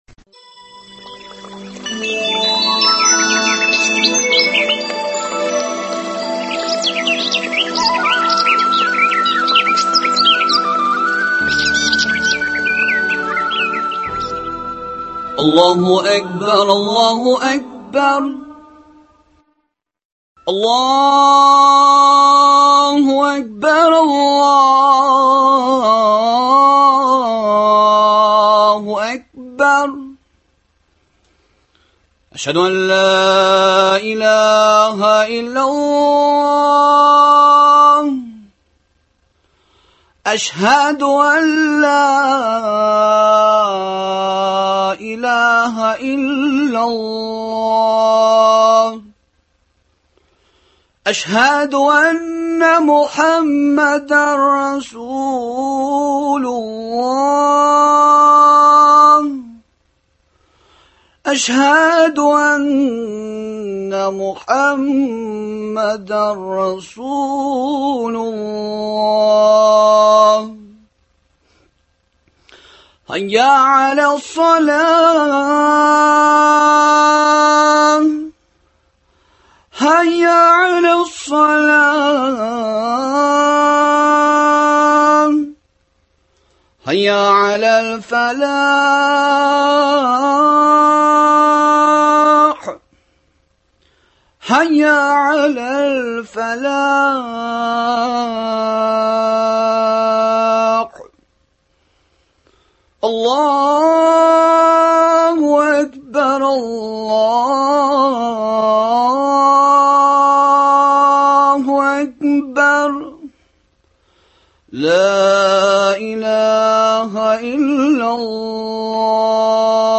Адәм баласының иң күркәм сыйфатларының берсе — сабырлык хакында әңгәмә.